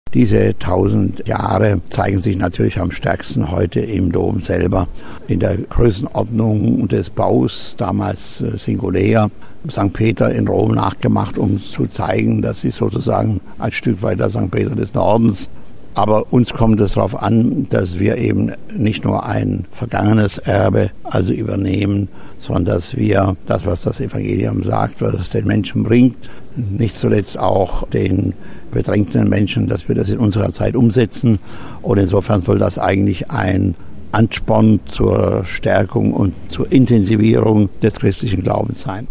In unserem Audio-Angebot: ein kurzer Gedanke von Kardinal Lehmann zur Bedeutung des Mainzer Doms.